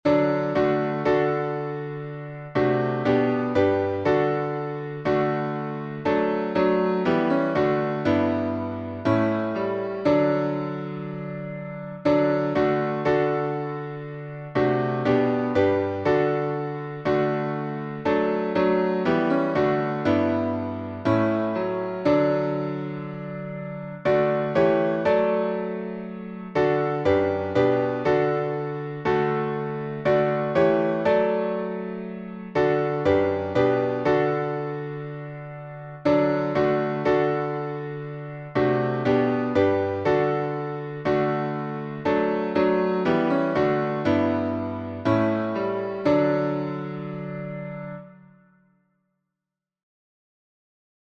Key signature: D major (2 sharps) Time signature: 3/2 Meter: 8.7.8.7.D.